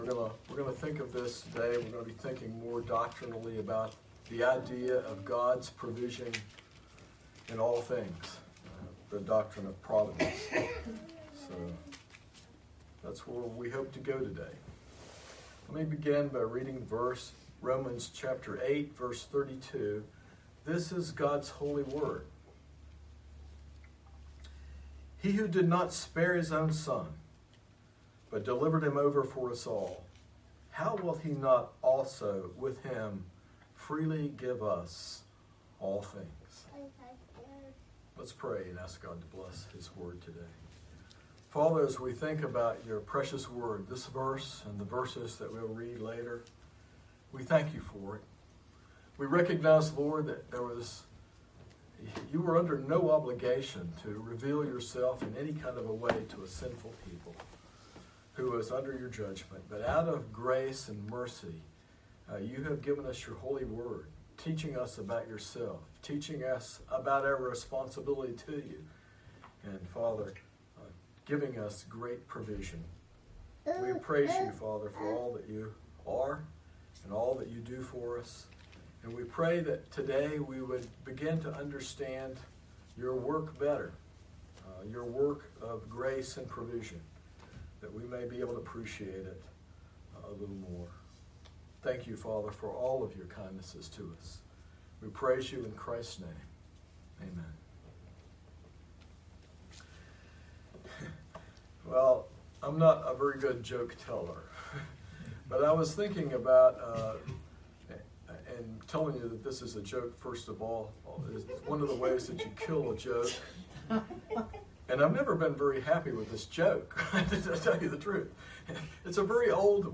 A sermon exploring the doctrine of God’s providence, examining how His hand is actively involved in all aspects of life, from the grand movements of history to the smallest details of creation.